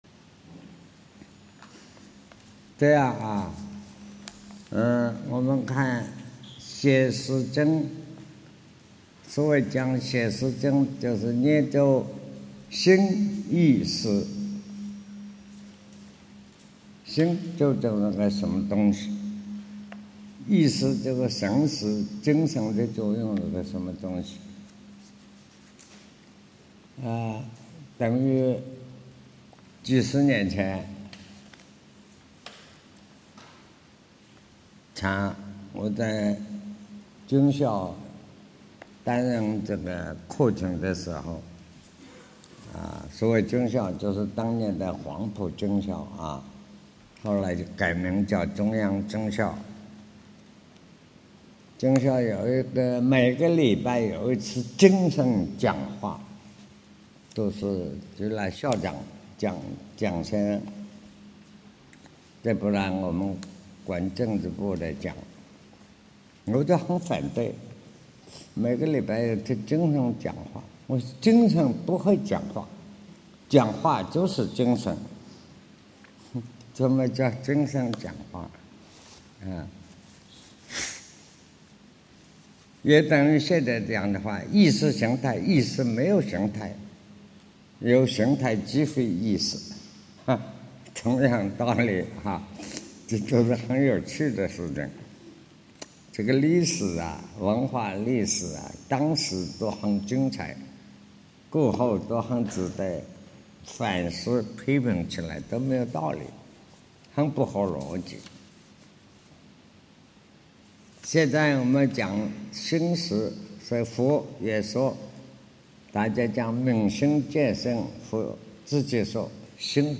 识与因果 南怀瑾先生讲大乘显识经(8)